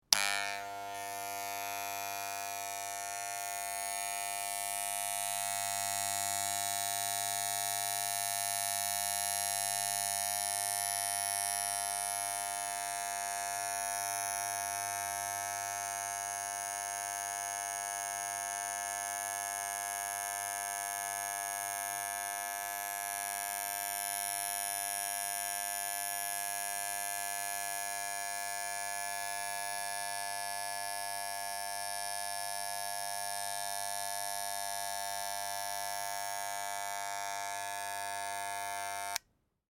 Braun Sixtant 1 shaver